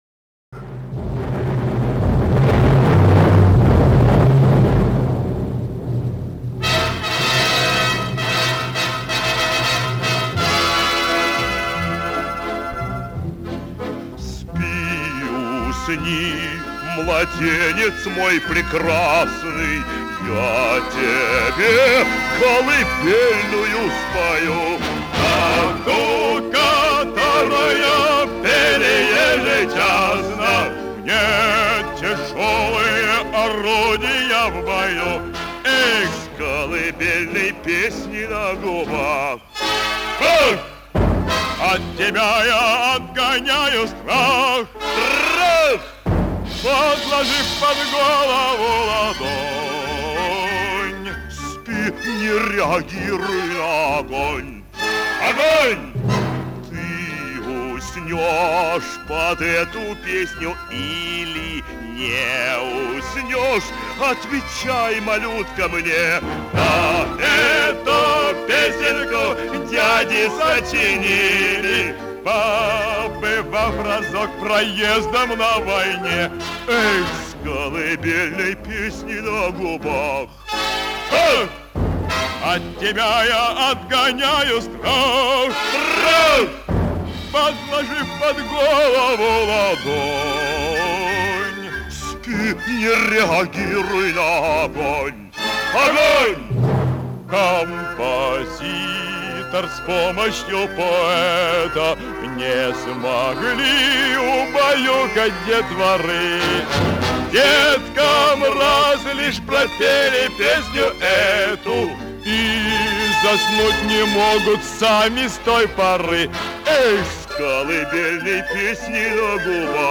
Оркестр особенно усыпляюще действует :)
Записано по радио